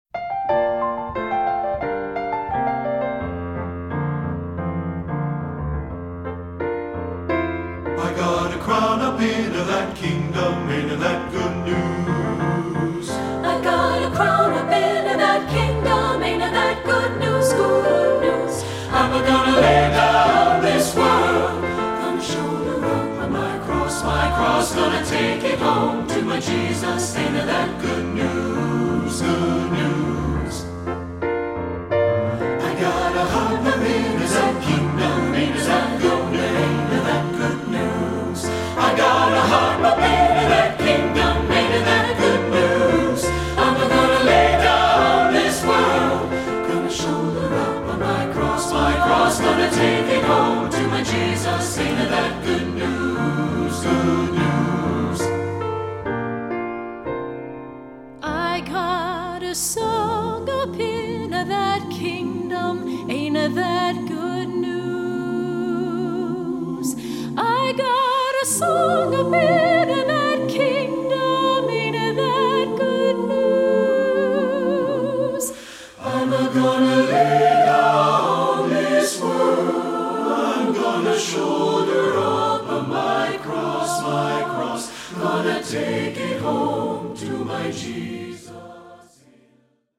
SATB
Choral Spiritual